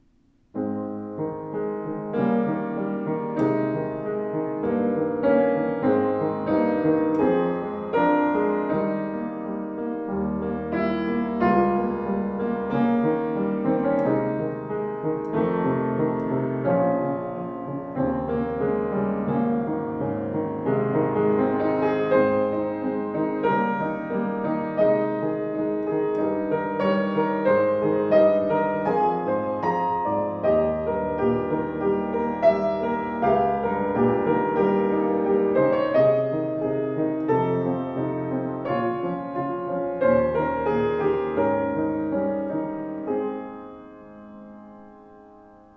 Erstaunlich voller und schöner Klang (das Modell 116 hat 118 cm Bauhöhe!) mit ausgewogenem Spielgefühl macht berührendes Musizieren möglich.
Klaviere